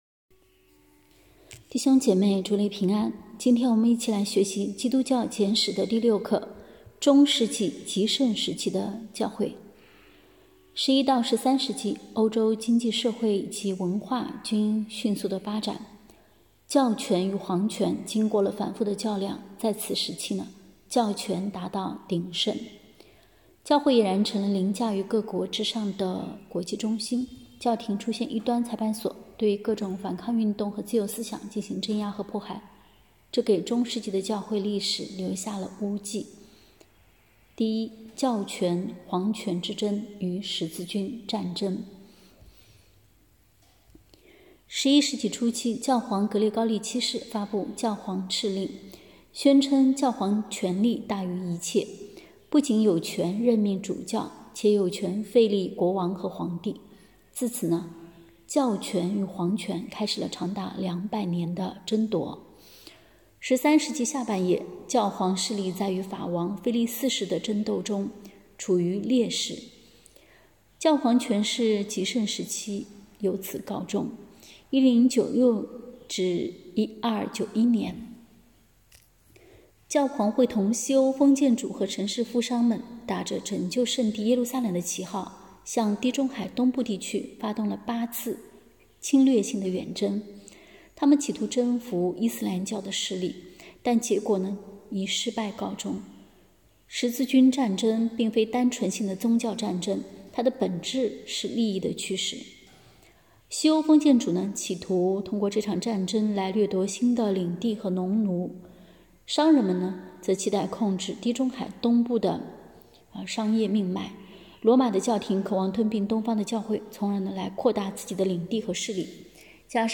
课程音频：